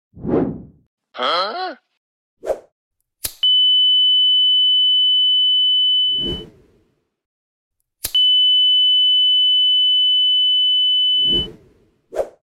Fire sensor alarm circuit | sound effects free download
Fire detector alarm